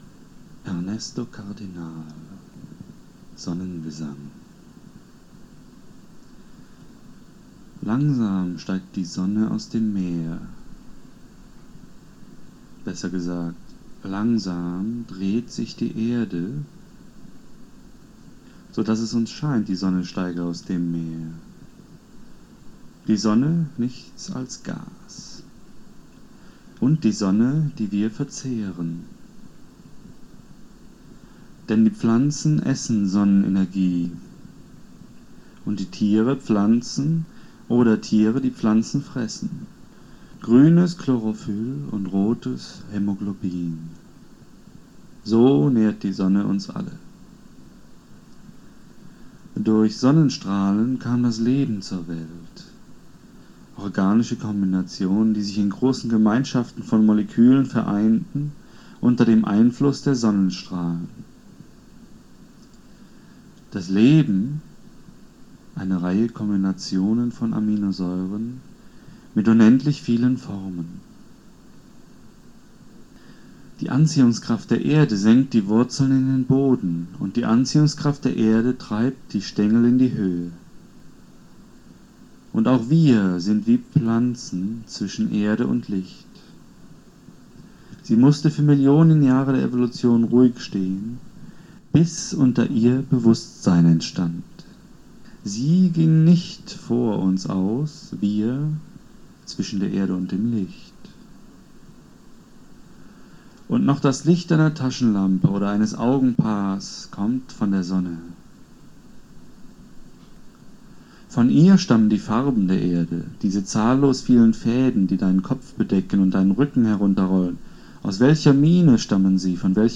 Hörbücher – Übersicht